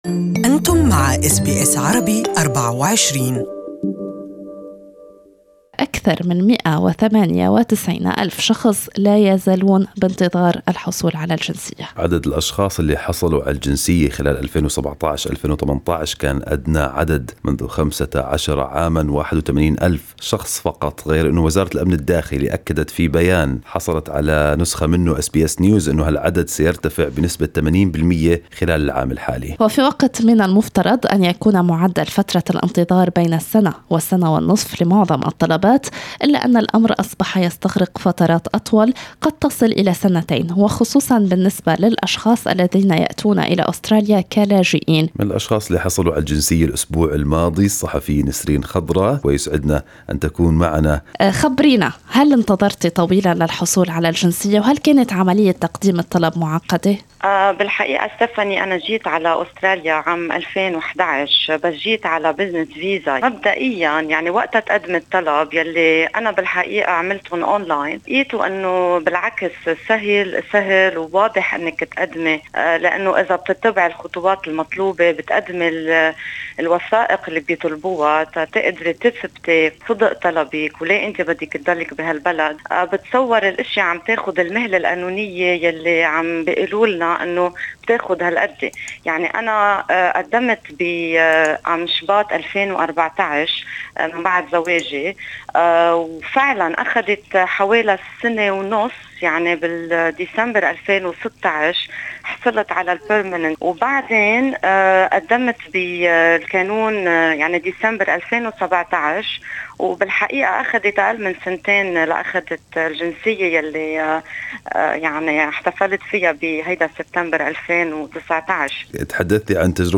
a Lebanese woman who recently received her Australian citizenship, speaks about the mixed feelings she's had upon the long-awaited day.